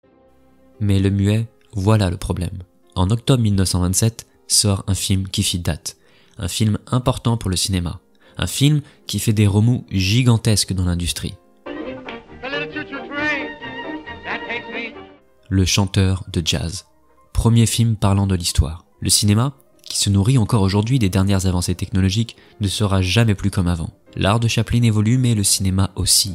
Narration historique